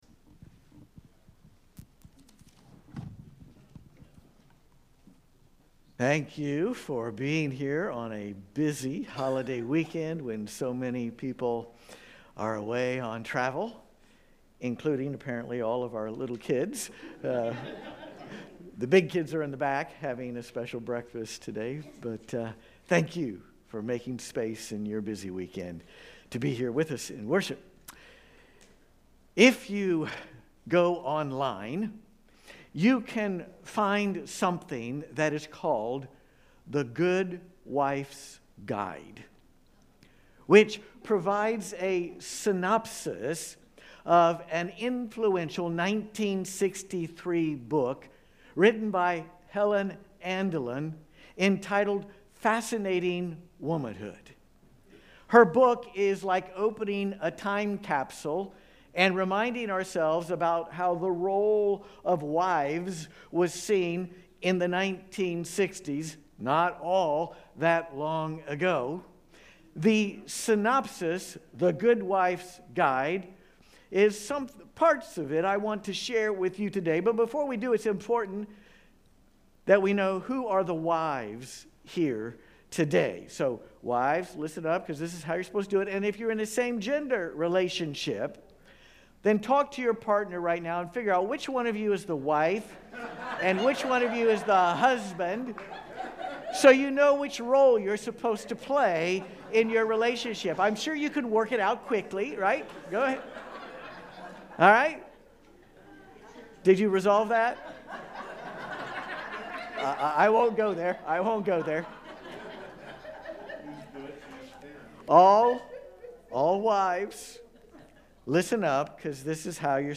If you've ever wondered how to deal with uncomfortable Bible passages, don't miss this sermon!